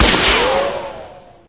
Media:Colt_super_01.wav 技能音效 super 发射子弹风暴音效